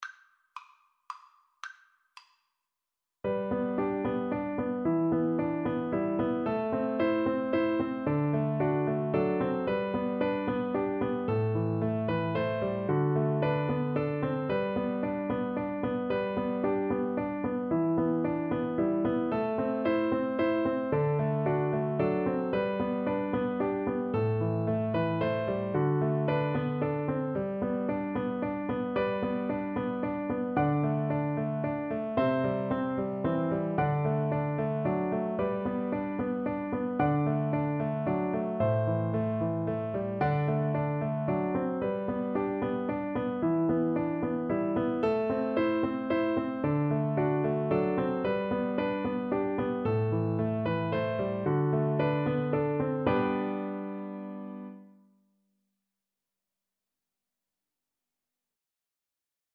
3/4 (View more 3/4 Music)
Moderately Fast ( = c. 112)
Traditional (View more Traditional Alto Recorder Music)